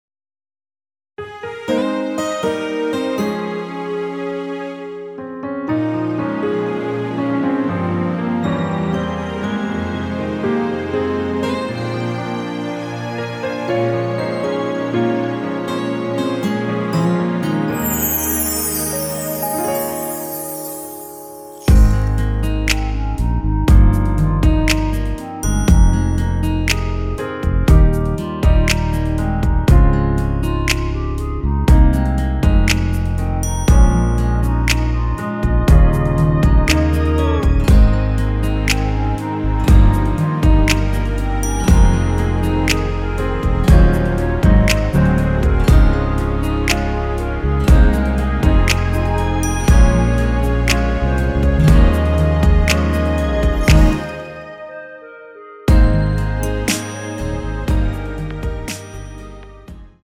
원키에서(-2)내린 멜로디 포함된 MR입니다.(미리듣기 참조)
Ab
멜로디 MR이라고 합니다.
앞부분30초, 뒷부분30초씩 편집해서 올려 드리고 있습니다.
중간에 음이 끈어지고 다시 나오는 이유는